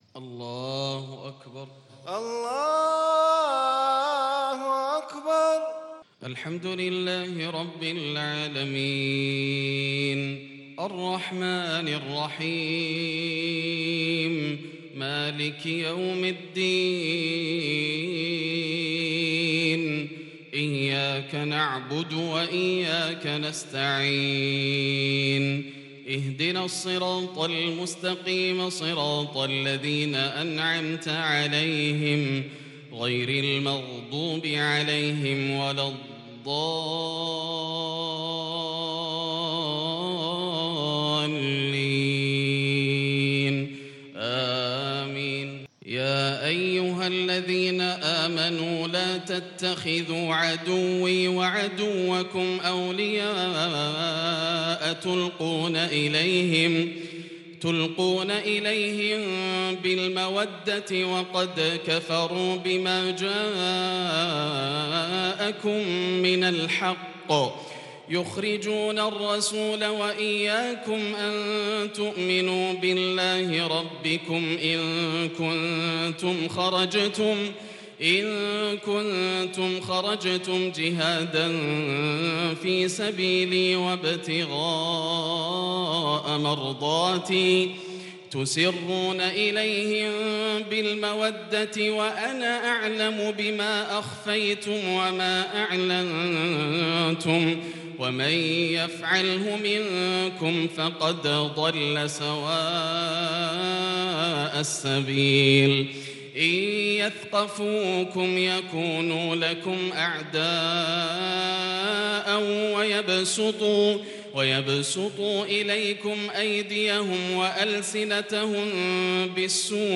صلاة الفجر للشيخ ياسر الدوسري 1 جمادي الأول 1442 هـ
تِلَاوَات الْحَرَمَيْن .